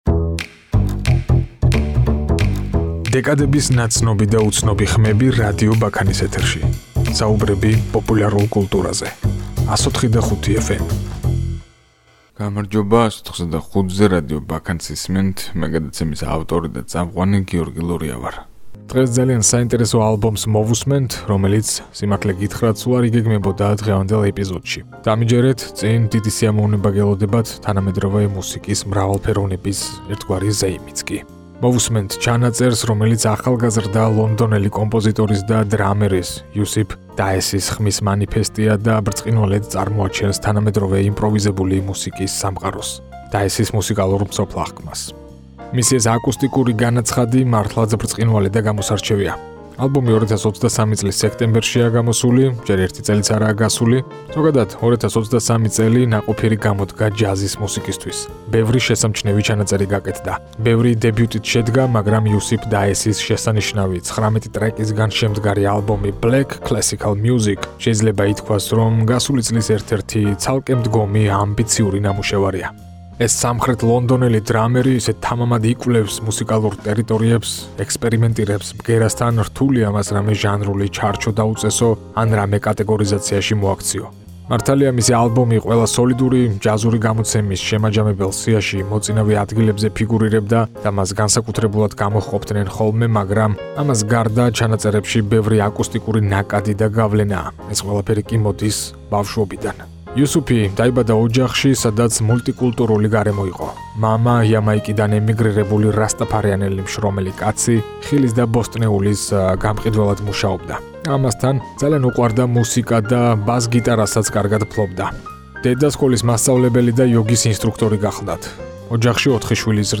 აკუსტიკური დებიუტი